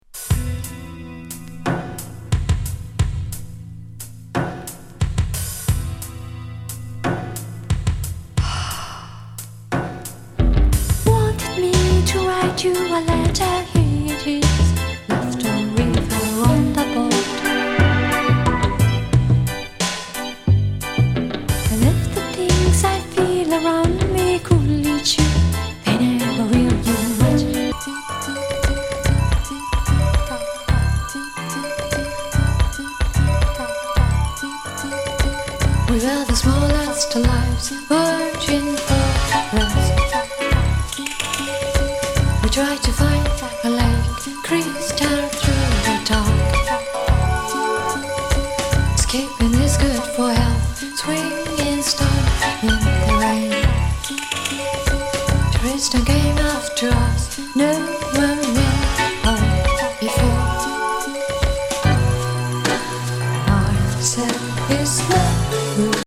エキセントリック・ウィスパー・レゲー